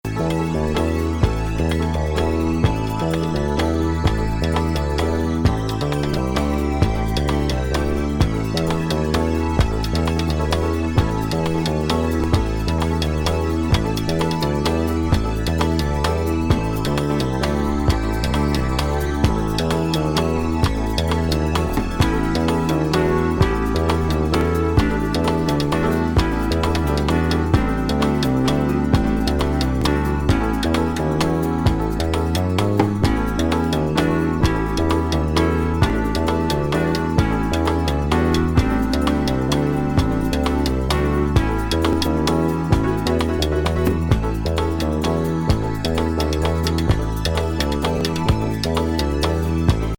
トロピカルなフランス産ライブラリーモノ。
・メロウ・フュージョン